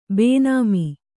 ♪ bēnāmi